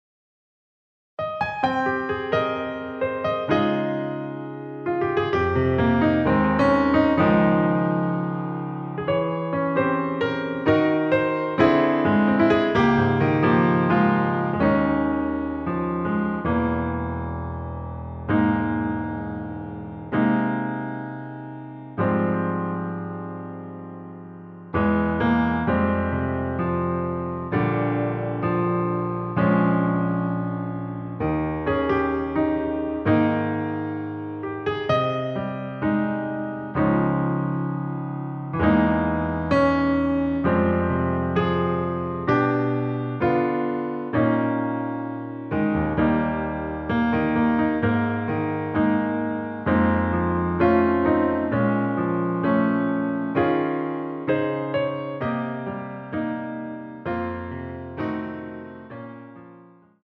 Ab
앞부분30초, 뒷부분30초씩 편집해서 올려 드리고 있습니다.
중간에 음이 끈어지고 다시 나오는 이유는